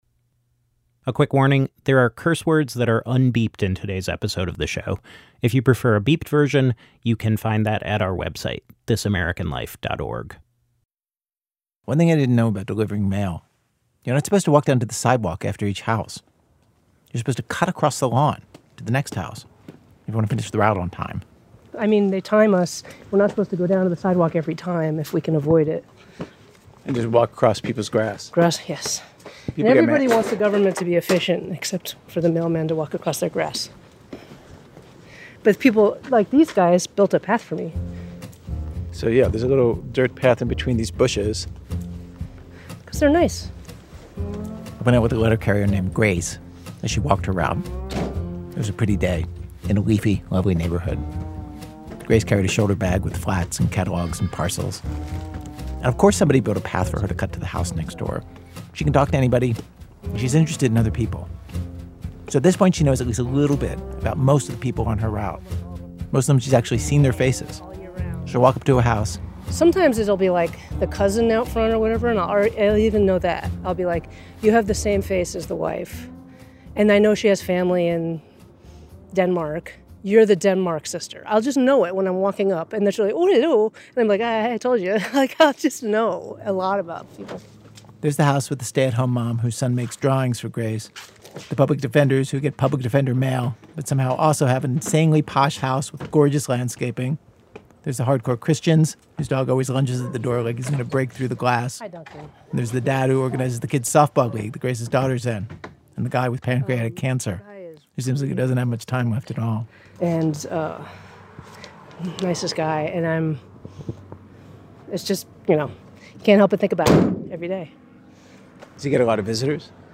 (3 minutes) This letter was performed by Taron Egerton at Union Chapel, London, for Letters Live , which stages live readings of famous and remarkable letters in front of an audience.
Note: The internet version of this episode contains un-beeped curse words.